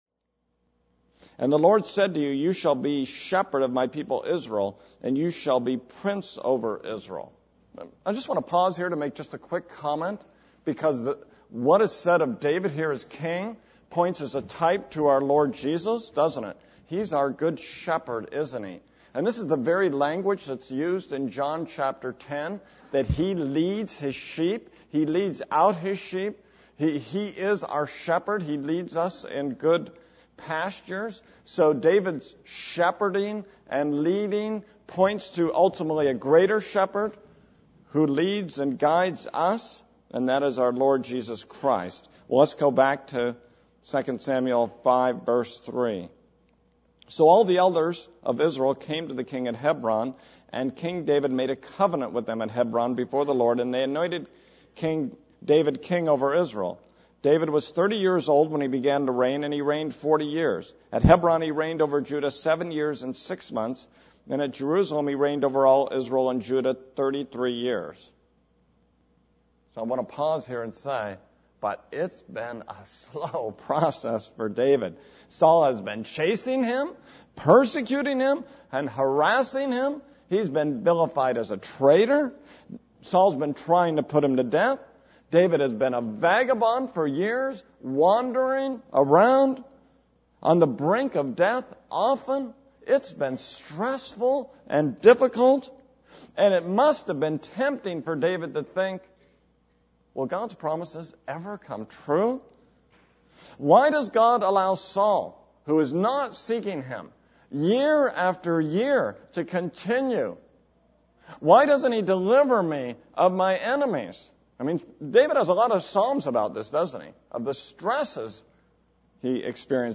This is a sermon on 2 Samuel 5:1-6:23.